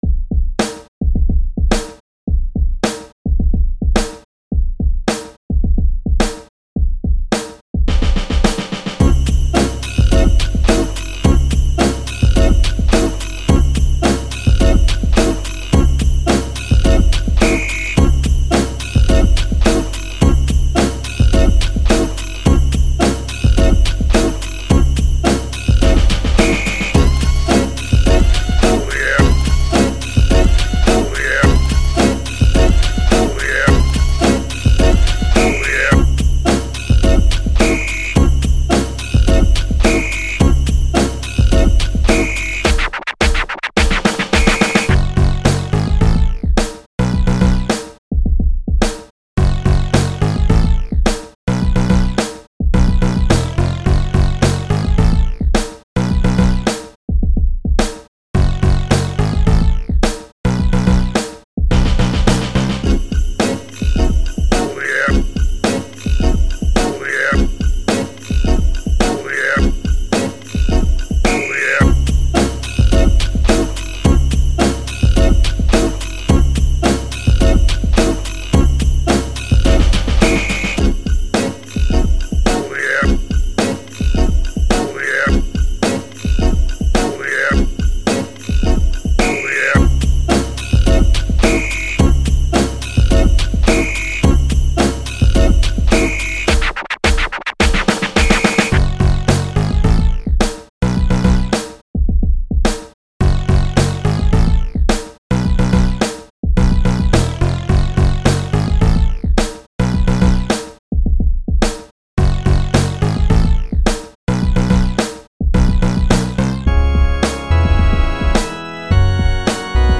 Instrumental - Lyrics